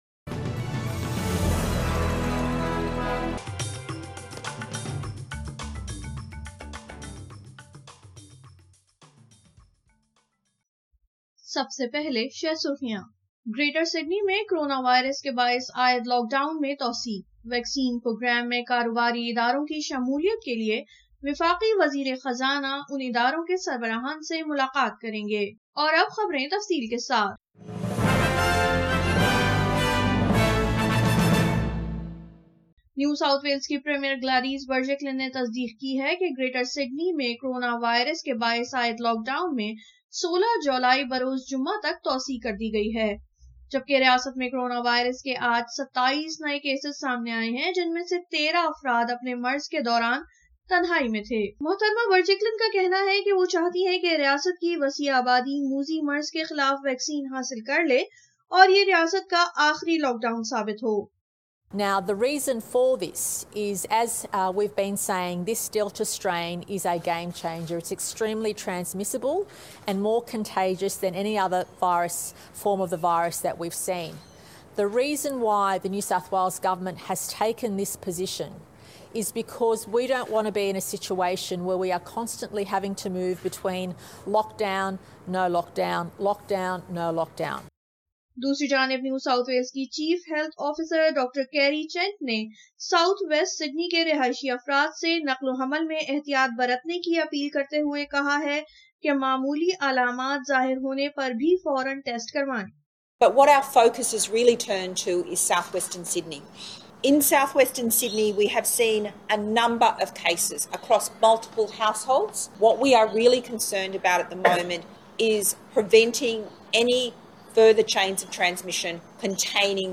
SBS Urdu News 07 July 2021